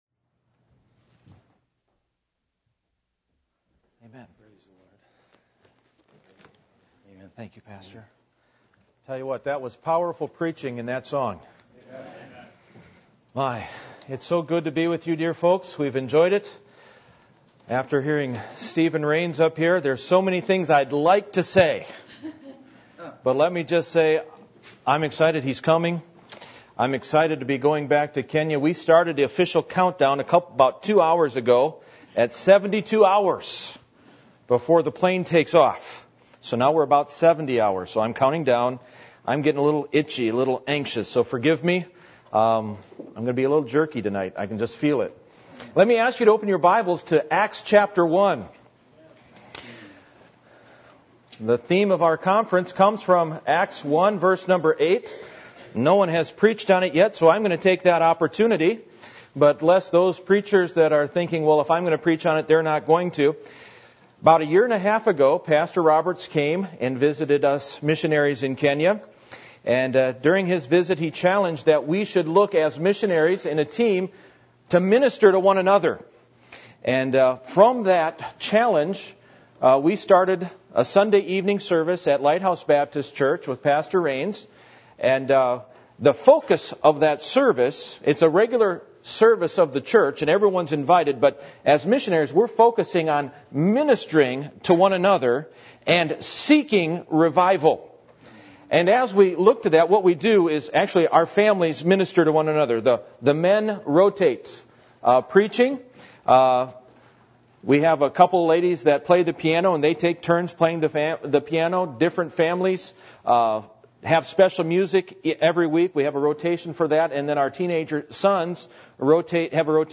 Acts 1:1-8 Service Type: Missions Conference %todo_render% « What Is Most Important To You?